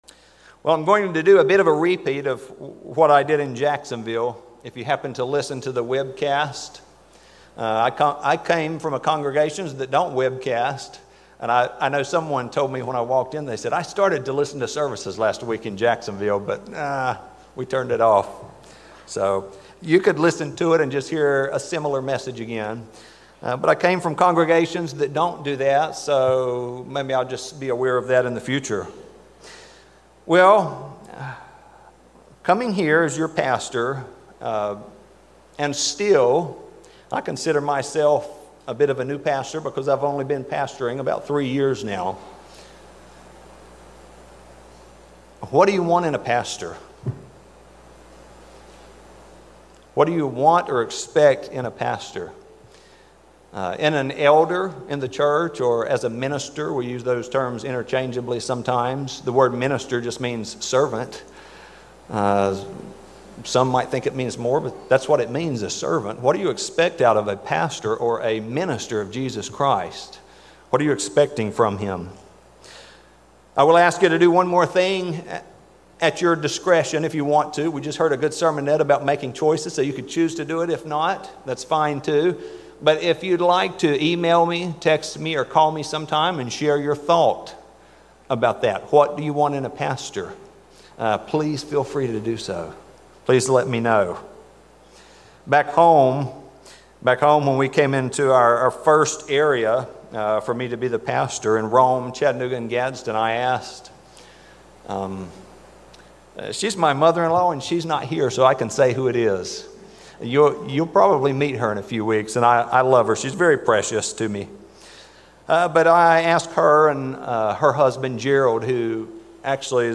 This sermon reviews the roles of being a pastor as God outlines in the Bible.